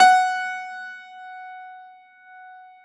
53f-pno16-F3.aif